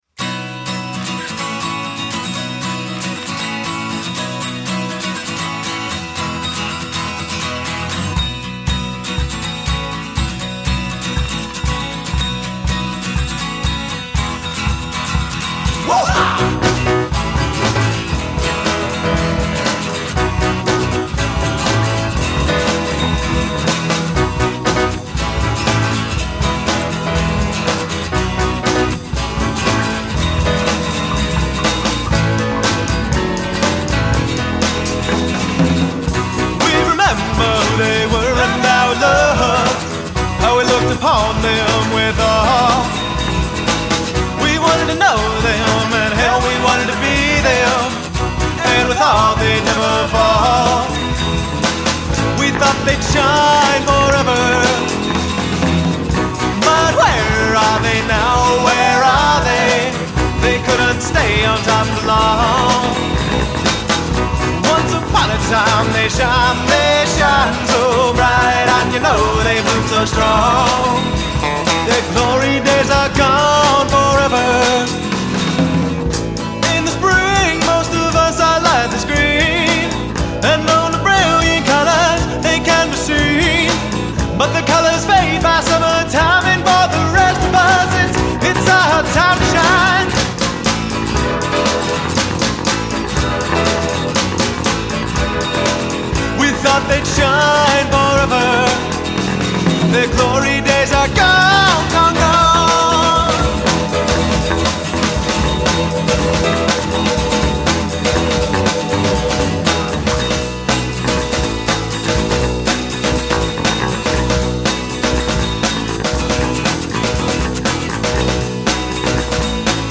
at Bayou Studios, Nashville, TN - January 2005
Guitar, Bass Guitar, Harmonica, Vocals
Drums
Piano, Organ